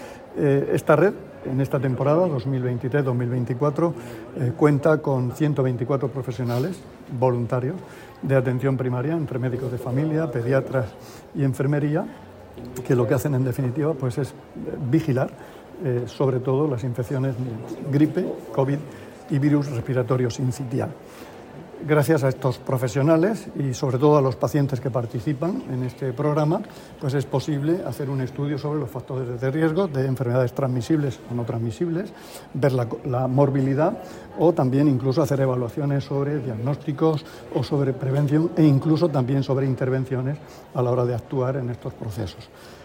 Declaraciones del consejero de Salud, Juan José Pedreño, sobre la Red Centinela Sanitaria de la Región de Murcia.
El consejero de Salud, Juan José Pedreño, inauguró las II Jornadas de la Red Centinela Sanitaria de la Región de Murcia celebradas en Murcia.